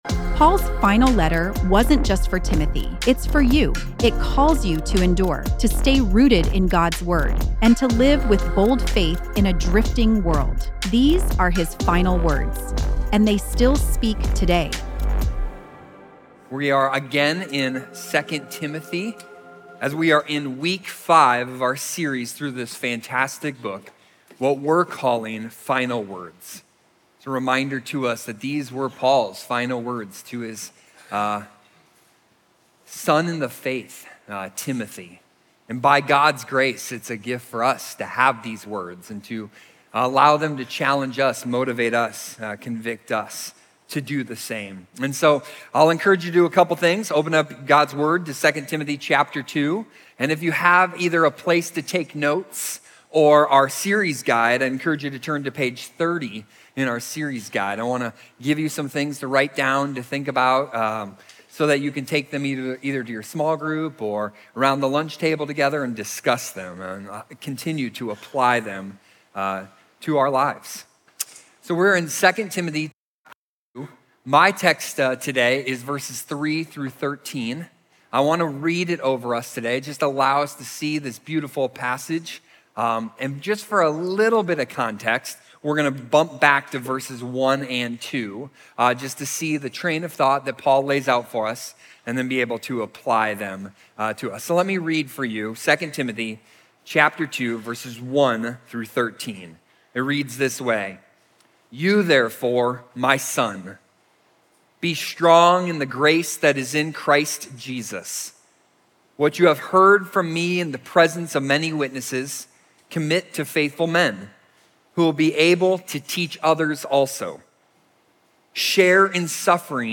The Holy Spirit shapes us for gospel suffering through gospel reminders. Listen to the latest sermon from our 2 Timothy series, Final Words, and learn more about the series here.